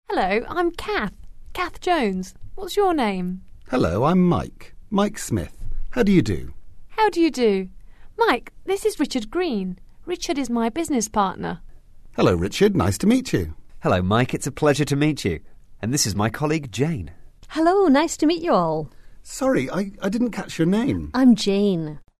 english_2_dialogue_1.mp3